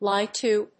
アクセントlíe tó
ライ‐ツー